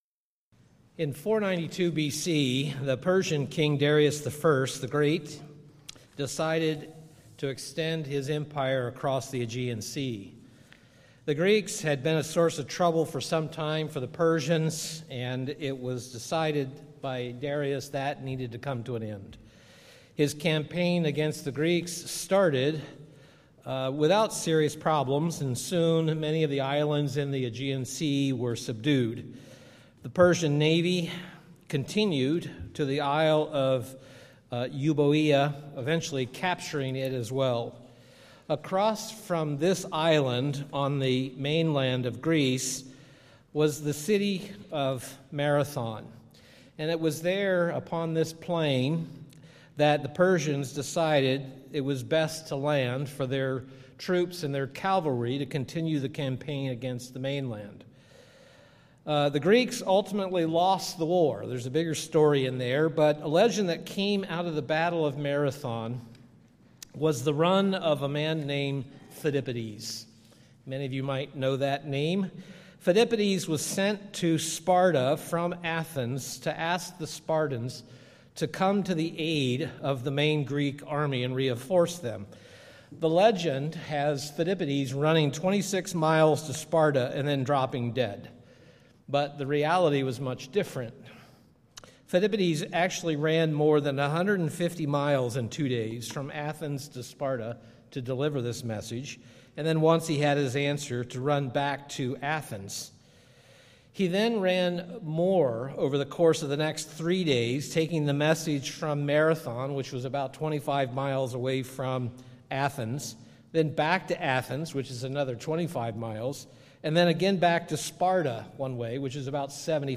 Sermons
Given in Milwaukee, WI